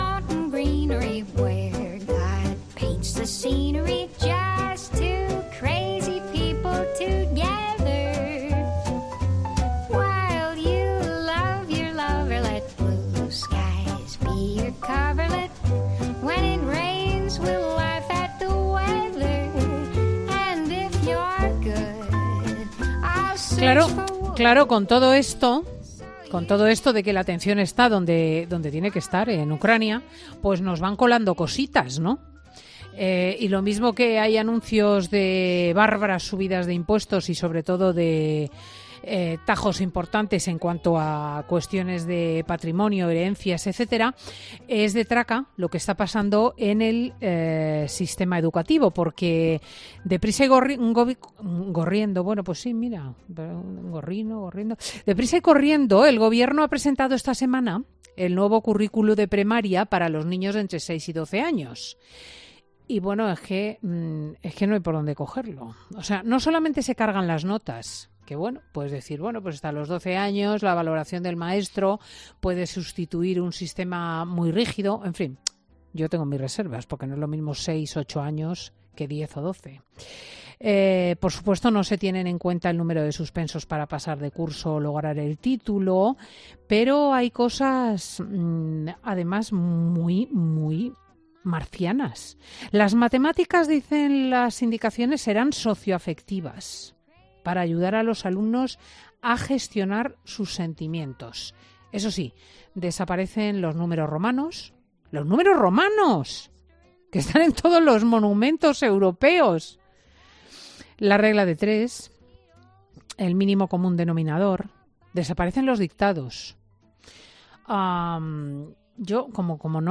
Enrique Ossorio, consejero de Educación en la Comunidad de Madrid, habla en Fin de Semana COPE sobre el nuevo currículo de Educación Primaria en...